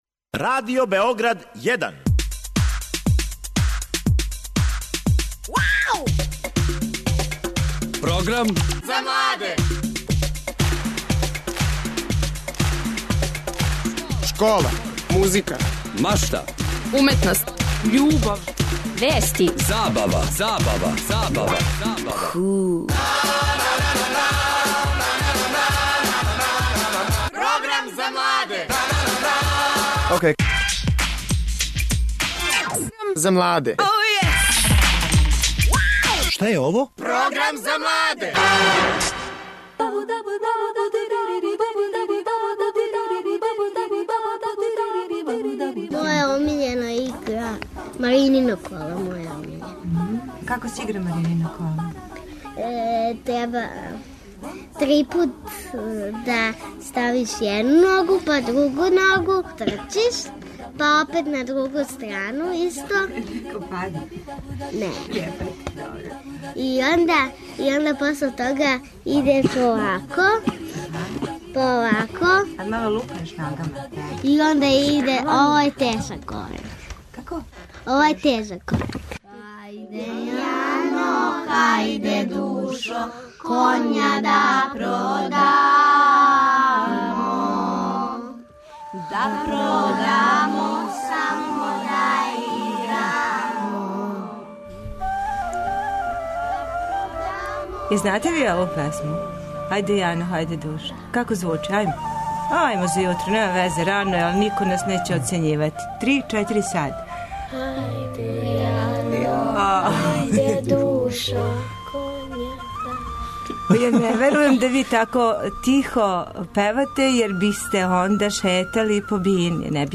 Деца из Дунавског обданишта знају да играју коло, а и да певају о њему.
Наши гости, деца и одрасли, долазе из КУД Извор.